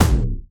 etfx_explosion_dark02.wav